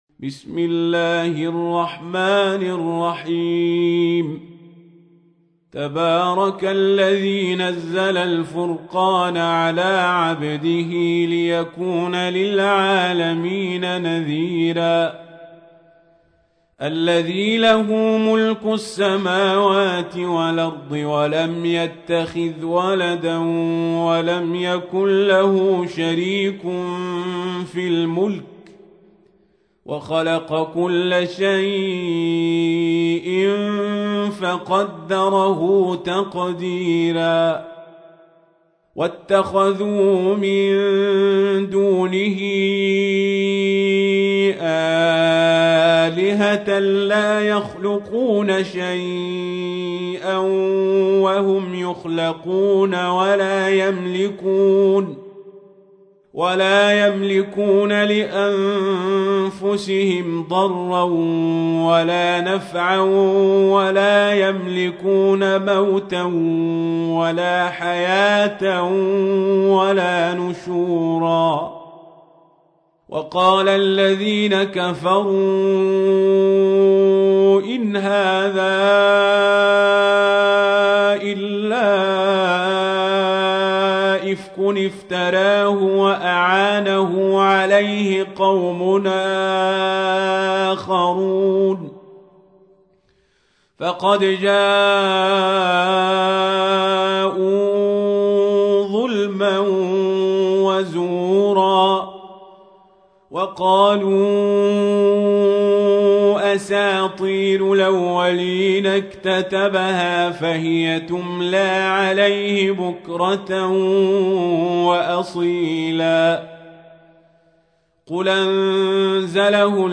تحميل : 25. سورة الفرقان / القارئ القزابري / القرآن الكريم / موقع يا حسين